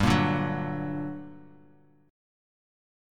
GmM7 chord